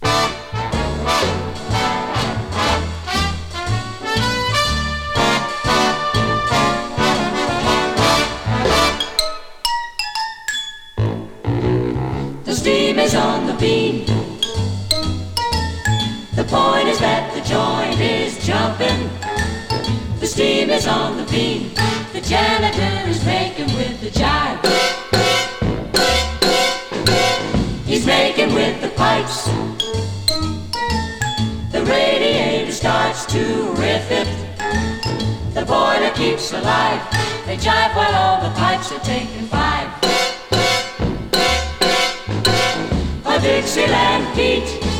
通して、芳醇で柔らかく滑らかで遊び心も織り交ぜた溜息漏れそう脱力するよな傑作です。
Jazz　USA　12inchレコード　33rpm　Mono